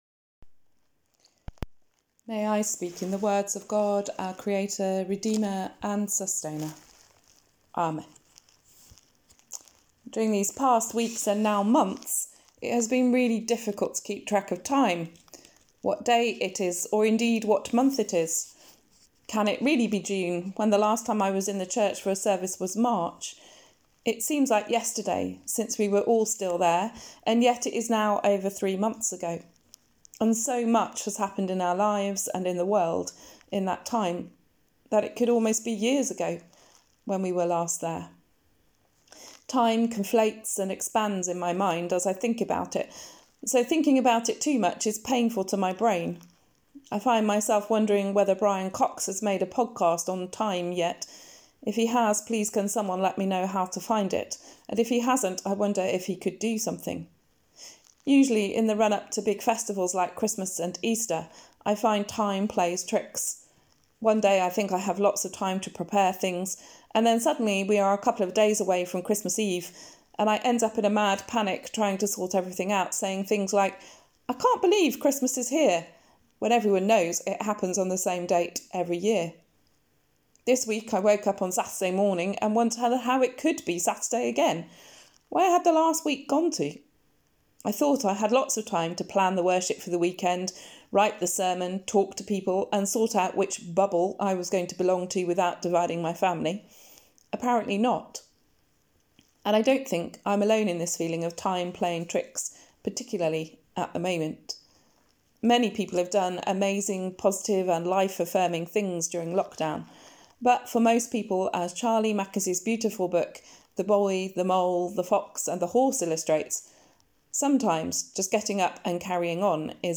Sermon: The Circular Dance of Time | St Paul + St Stephen Gloucester